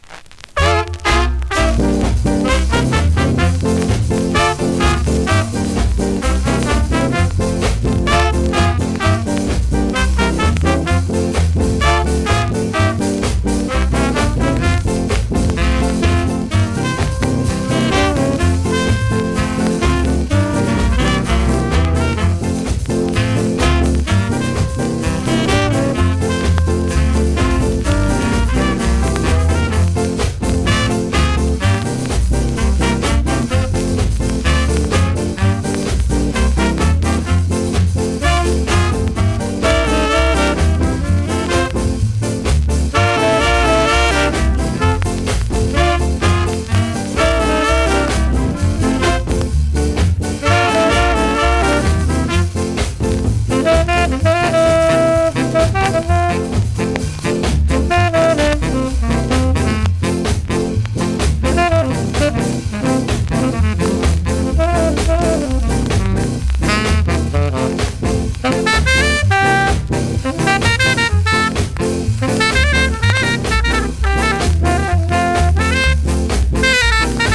〜VG+ コメントMEGA RARE SKA!!
スリキズ、ノイズ比較的少なめで